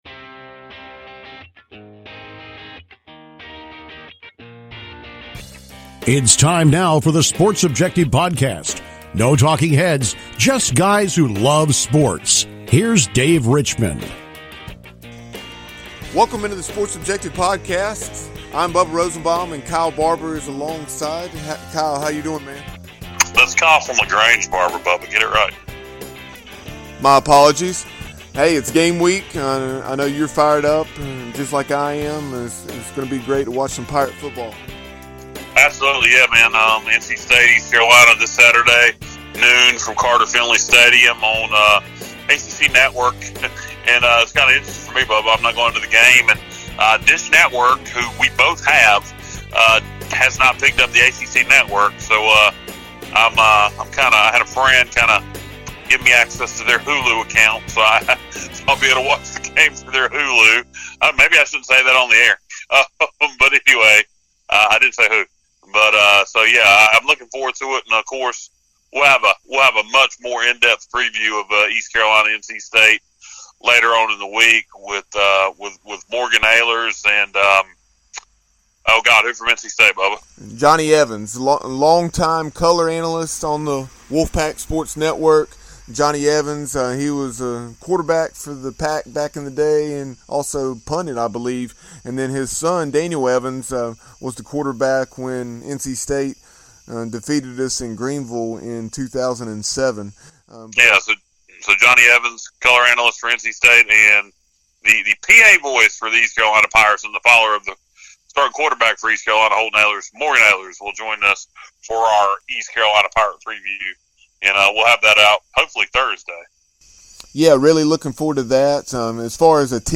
With Week 1 of the college football season upon us, we caught up with legendary broadcast Tim Brando of Fox Sports to talk a variety of topics.